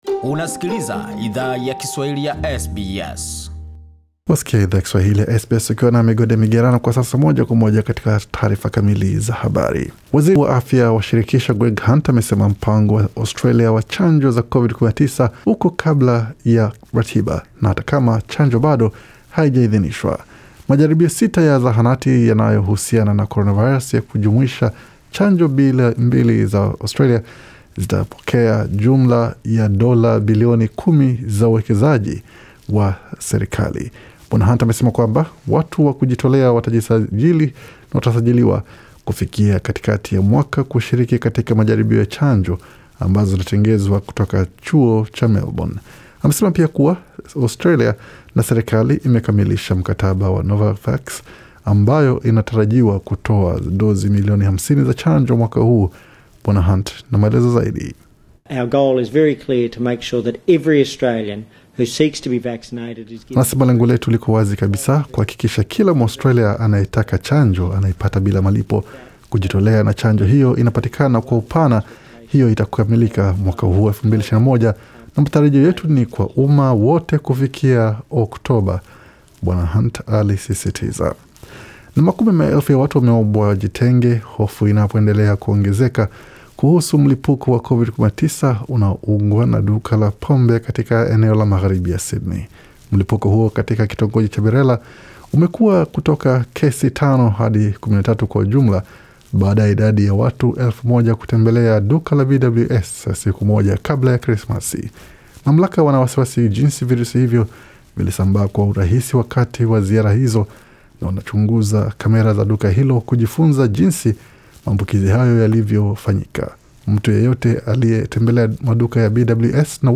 Taarifa ya habari 3 Januari 2021